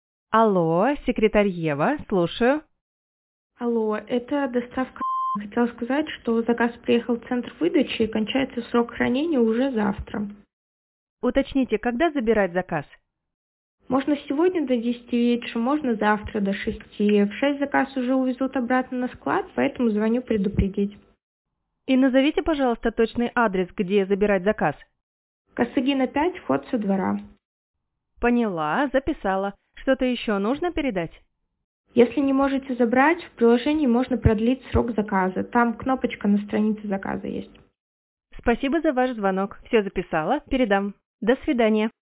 Она работает на базе искусственного интеллекта и обучена ведению разговора.
Ева разговаривает не как автоответчик, а как умный ассистент, то есть вежливо и реалистично.
🔈 Пример разговора Евы со службой доставки: